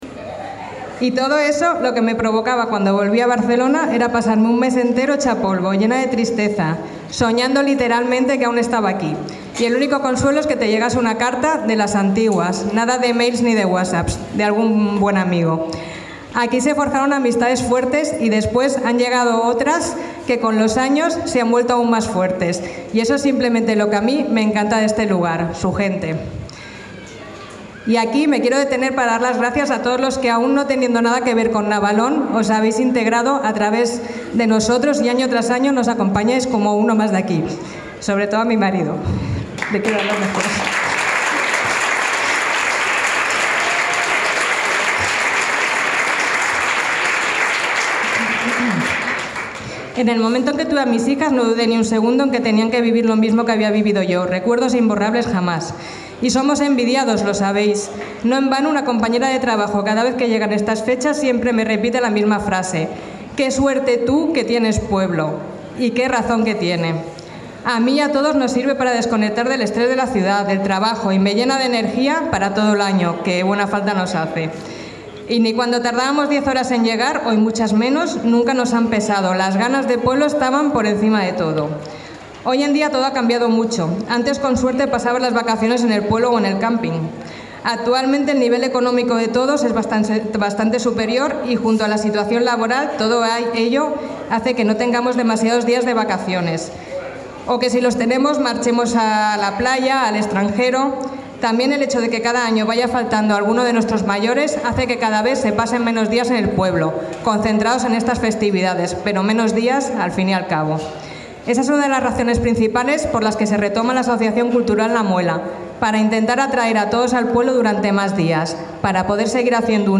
pregon-reeee.mp3